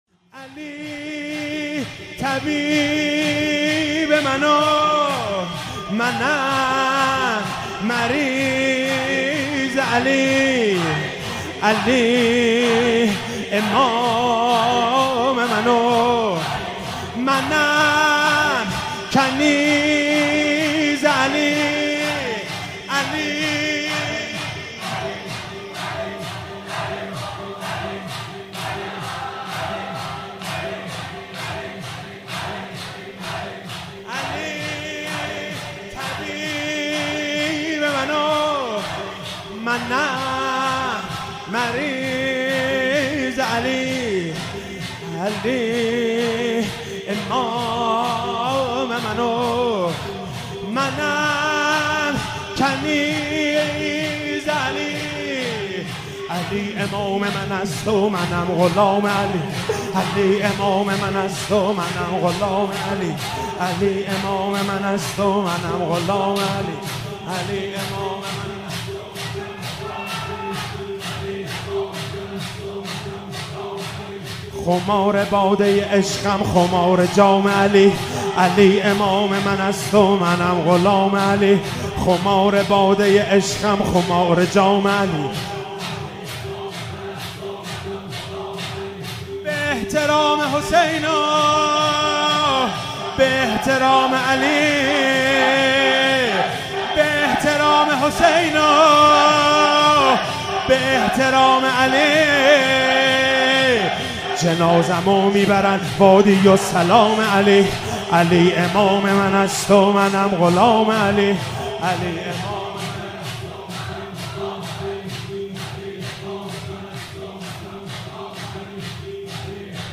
فاطمیه 96 - شب هشتم - شور - علی طبیب من و منم مریض علی